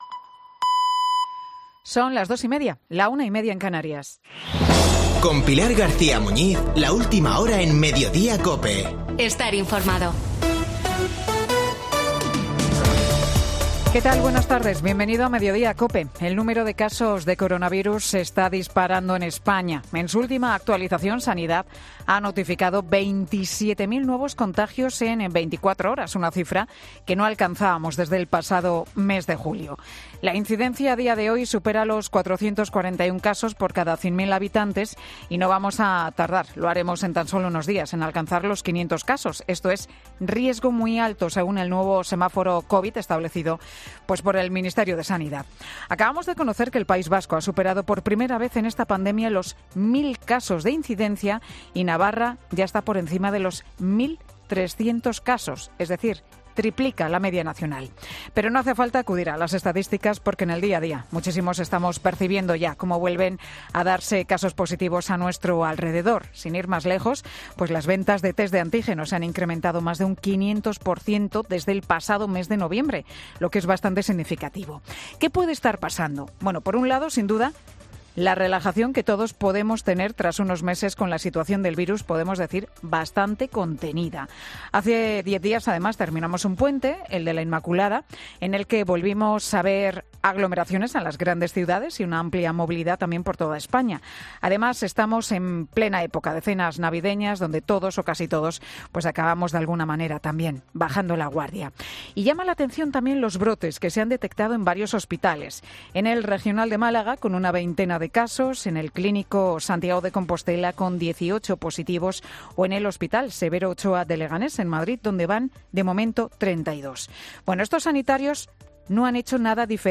El monólogo de Pilar García Muñiz en Mediodía COPE